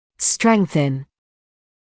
[‘streŋθn][‘стрэнсн]усиливать(ся); укреплять(ся)